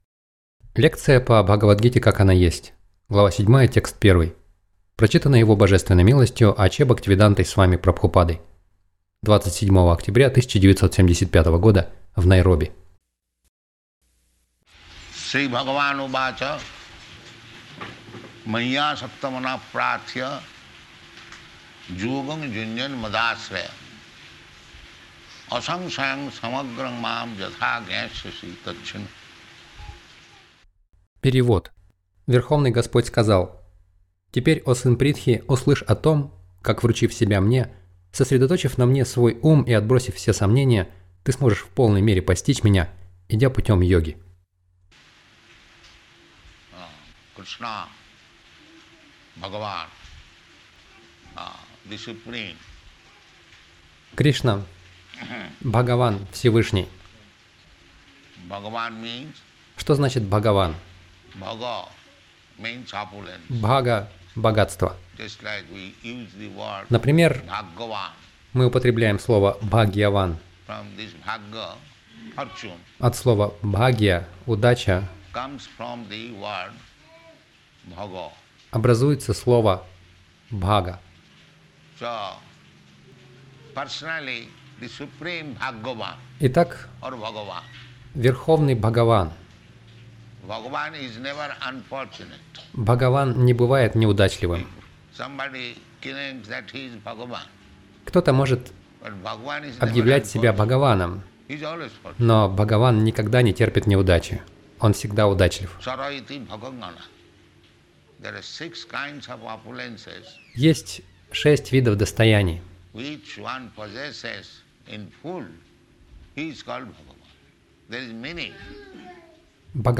Милость Прабхупады Аудиолекции и книги 27.10.1975 Бхагавад Гита | Найроби БГ 07.01 — Привяжитесь к Кришне Загрузка...